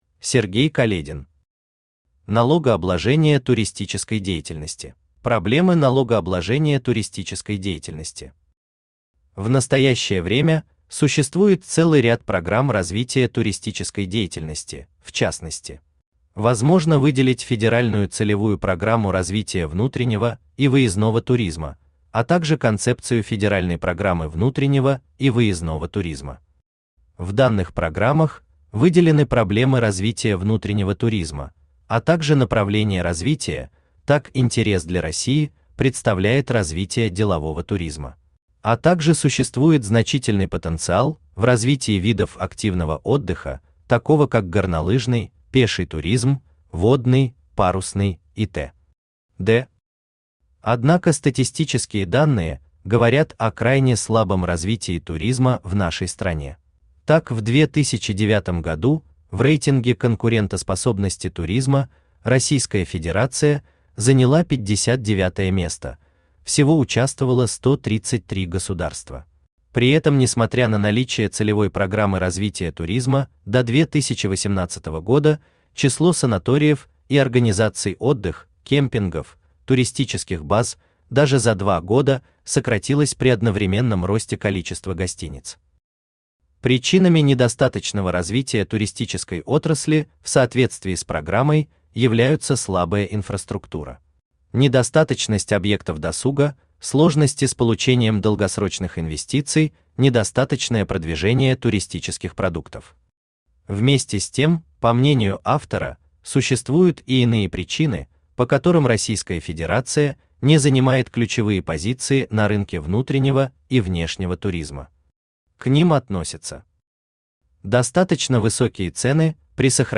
Аудиокнига Налогообложение туристической деятельности | Библиотека аудиокниг
Aудиокнига Налогообложение туристической деятельности Автор Сергей Каледин Читает аудиокнигу Авточтец ЛитРес.